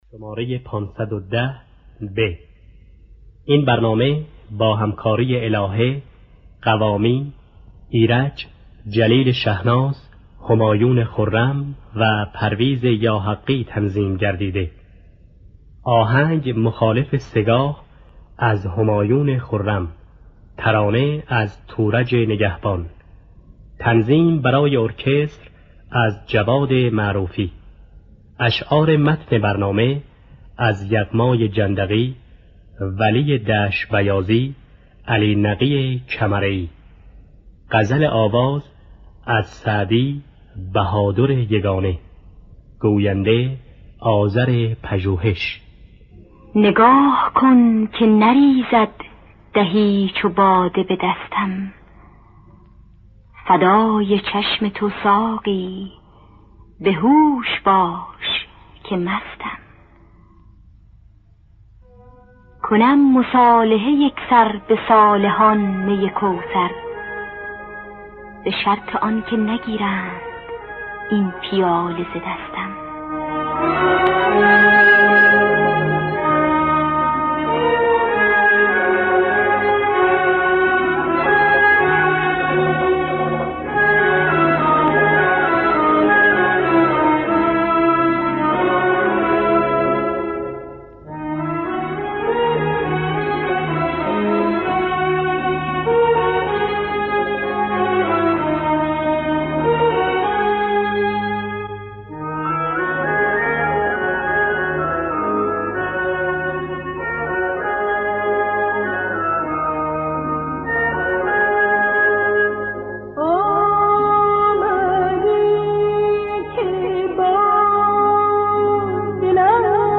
دانلود گلهای رنگارنگ ۵۱۰ب با صدای الهه، ایرج، حسین قوامی در دستگاه سه‌گاه. آرشیو کامل برنامه‌های رادیو ایران با کیفیت بالا.